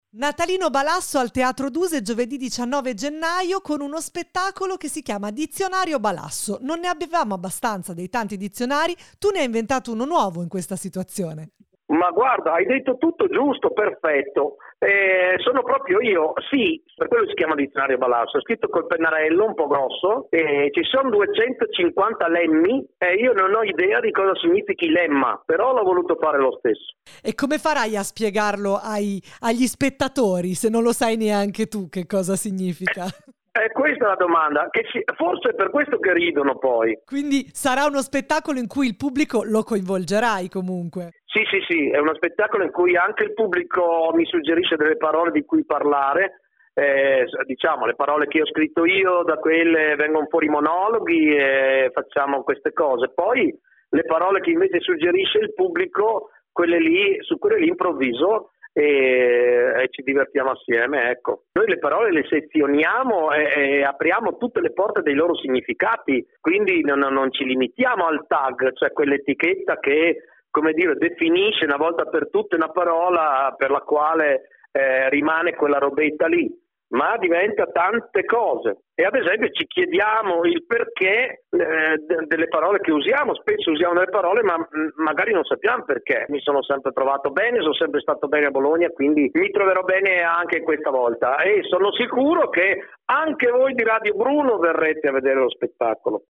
Sentiamo Balasso raggiunto al telefono